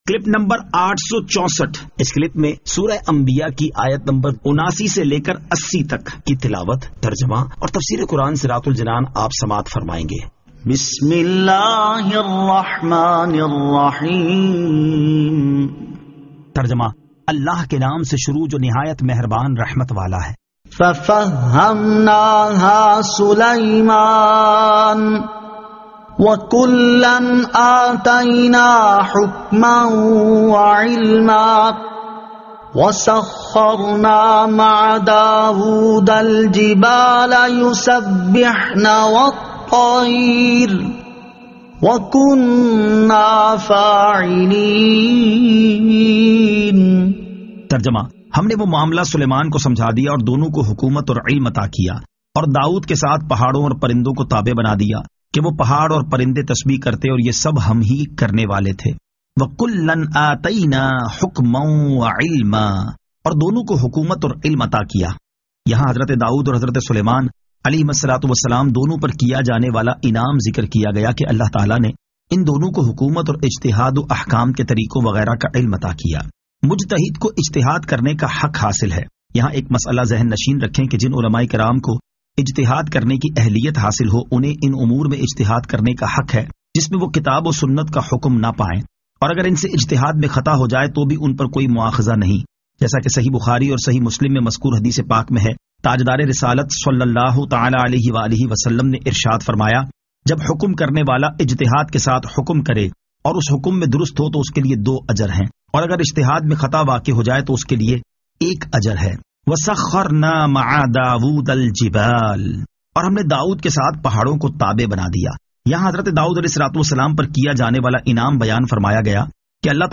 Surah Al-Anbiya 79 To 80 Tilawat , Tarjama , Tafseer